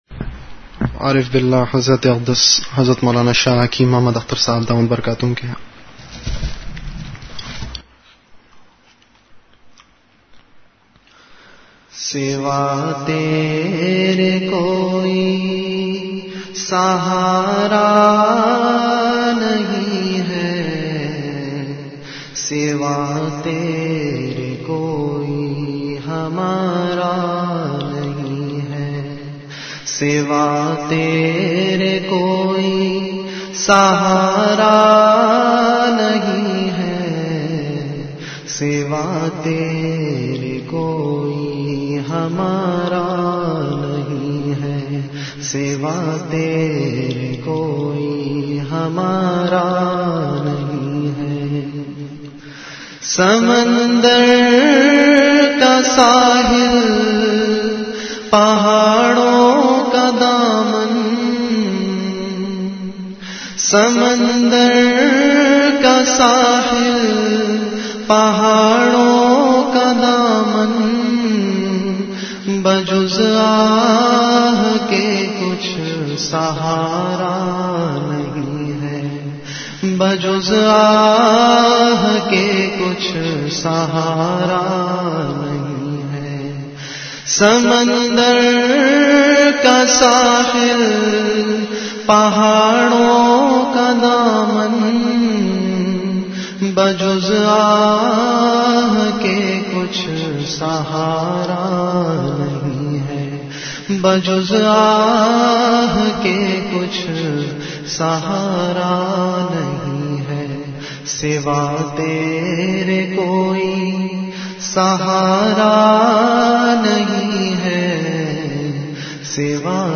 CategoryAshaar
Event / TimeAfter Isha Prayer